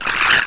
slv_pain1.wav